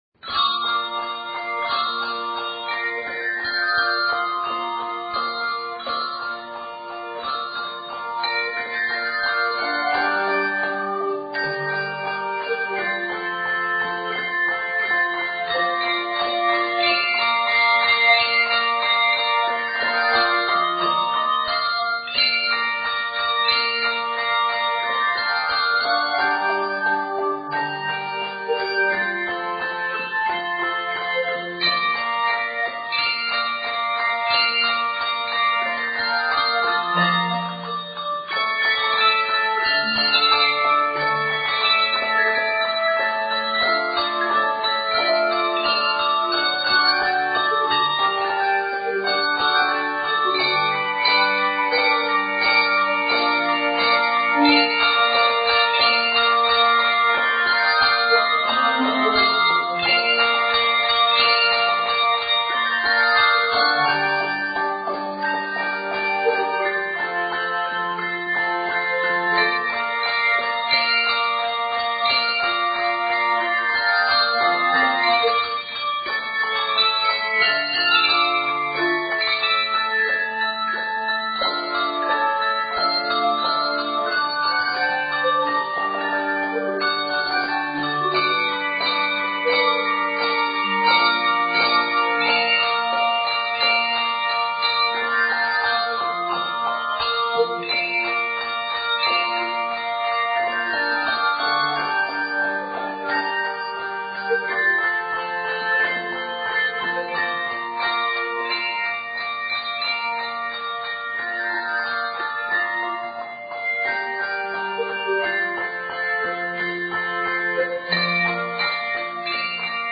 Octaves: 5-6